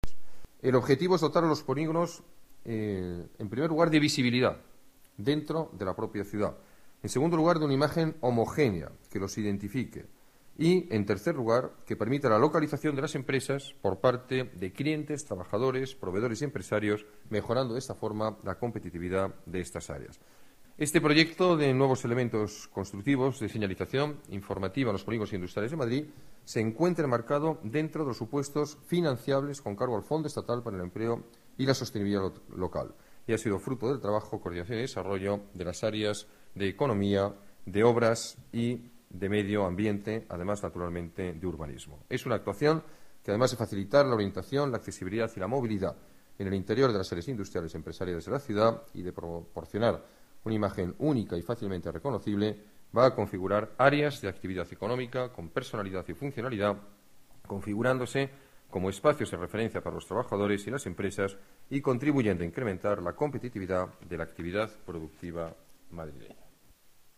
Nueva ventana:Declaraciones alcalde, Alberto Ruiz-Gallardón: señalización polígonos industriales